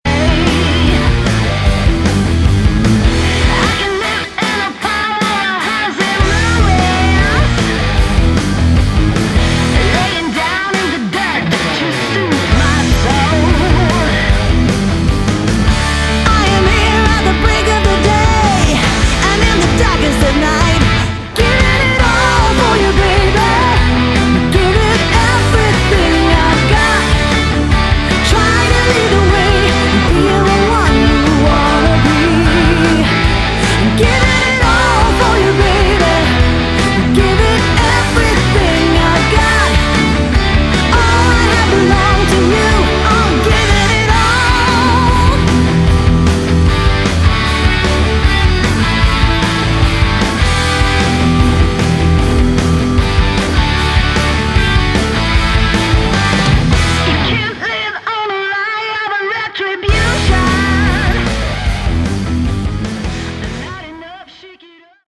Category: Hard Rock
vocals
guitars
bass guitar
drums